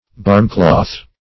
barmcloth - definition of barmcloth - synonyms, pronunciation, spelling from Free Dictionary Search Result for " barmcloth" : The Collaborative International Dictionary of English v.0.48: Barmcloth \Barm"cloth`\ (b[aum]rm"kl[o^]th), n. Apron.